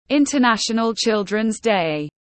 Ngày quốc tế thiếu nhi tiếng anh gọi là International Children’s Day, phiên âm tiếng anh đọc là /ˌɪntərˈnæʃənl ˈʧɪldrənz deɪ/
International Children’s Day /ˌɪntərˈnæʃənl ˈʧɪldrənz deɪ/